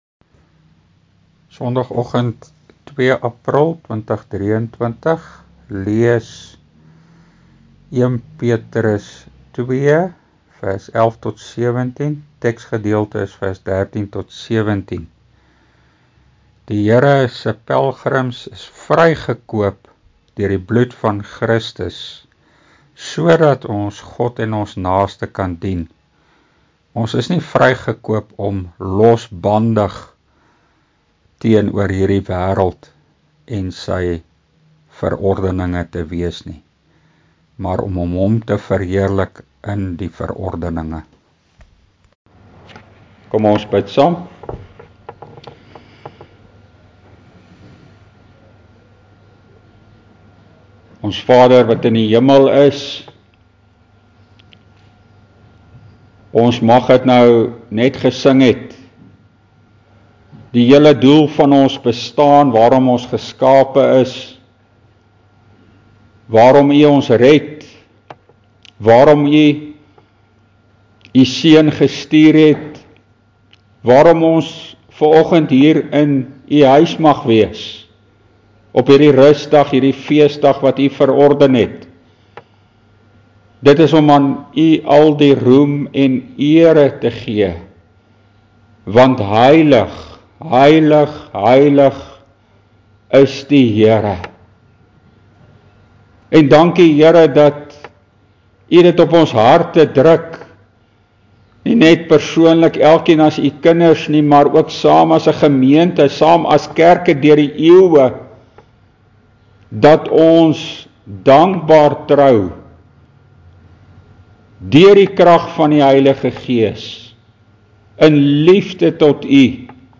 1 PETRUS 2 PREKE: (12)